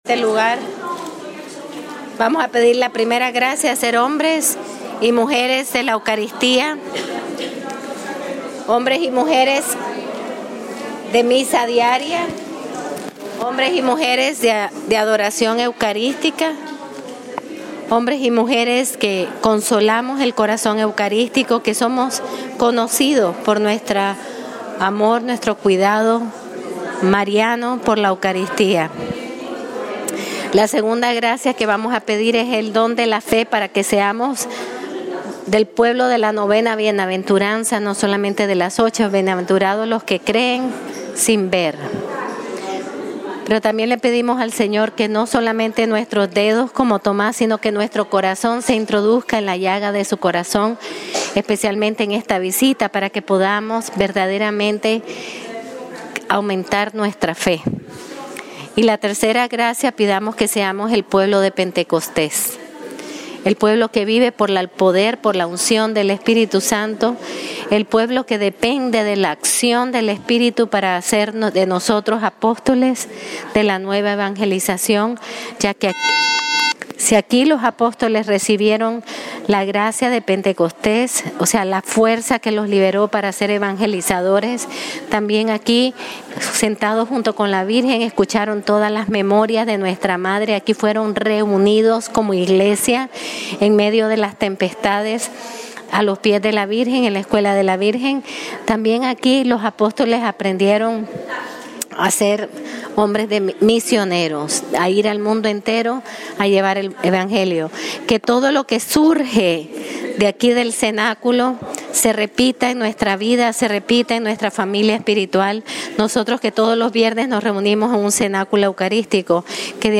"Jesús vence el mal con opciones heroicas de amor" - Escuelita en San Pedro Gallicantum